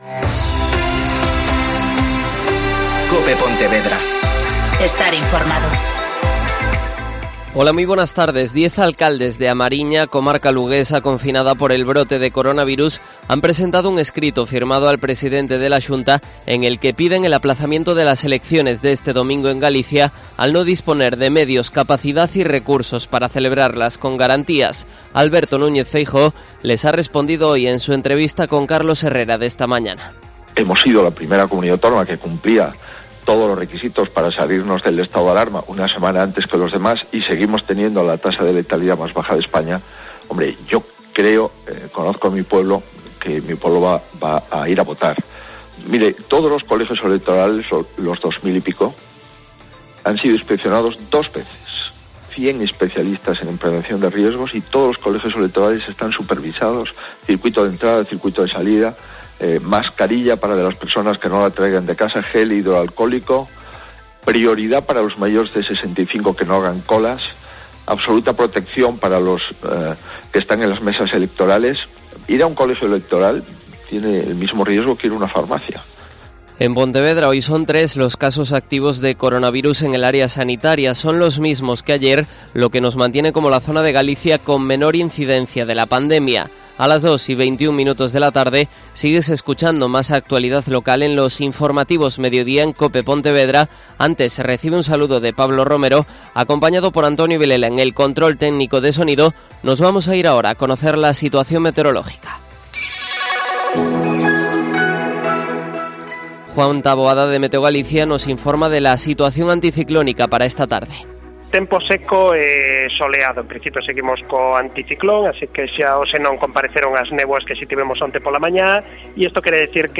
Mediodia COPE Pontevedra (informativo 14:20h)